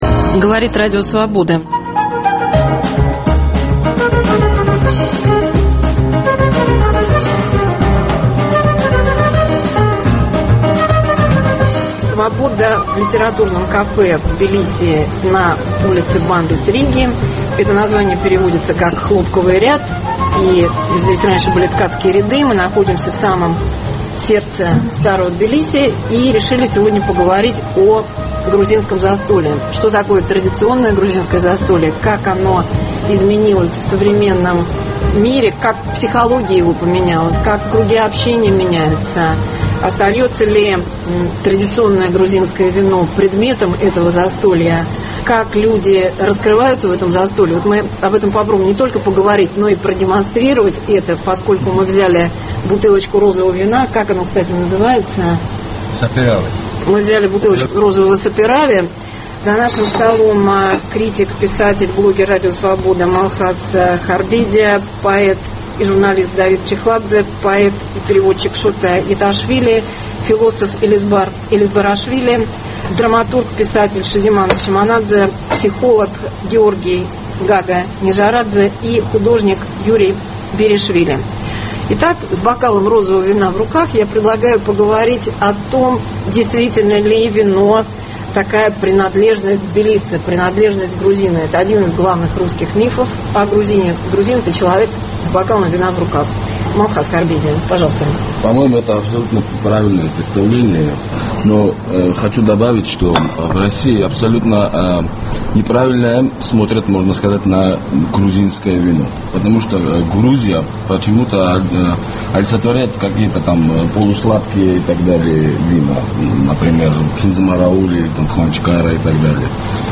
Литературное кафе на Бамбис риги, Тбилиси. Что такое традиционное грузинское застолье, как оно изменилось в современном мире, как поменялась его психология, круги общения?